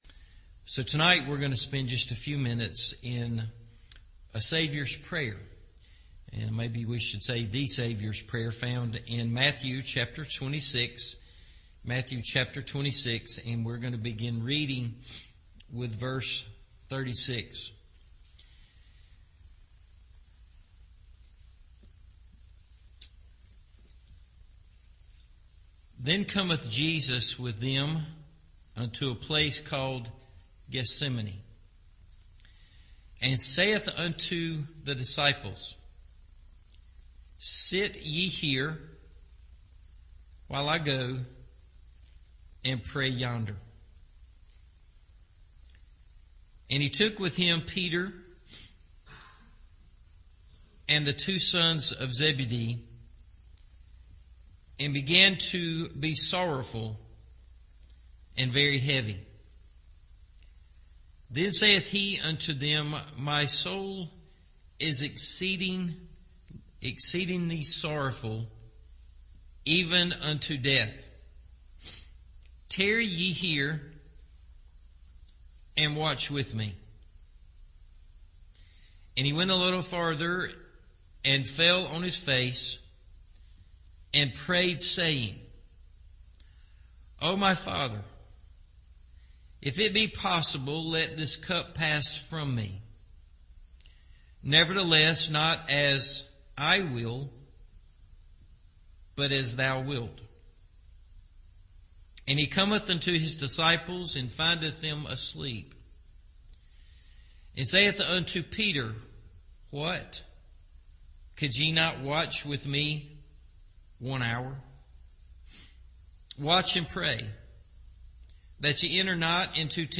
The Saviour’s Prayer – Evening Service